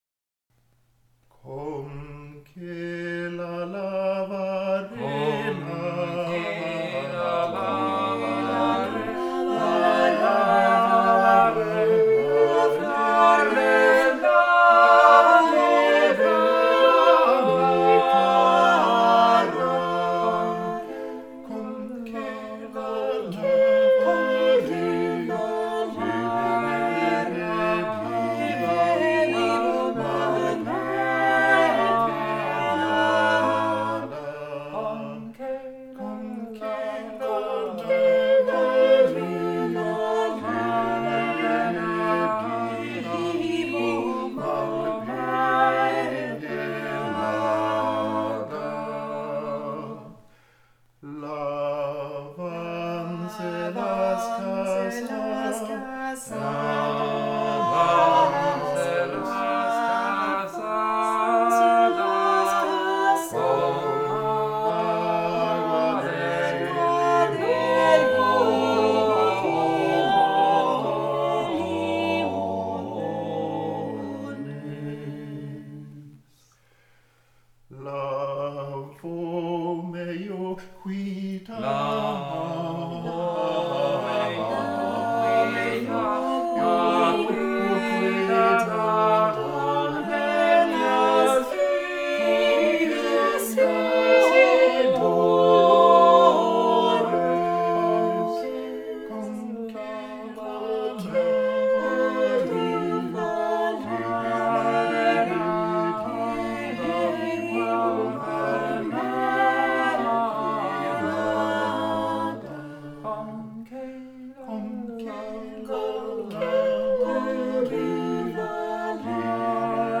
Vokalensemblen KALK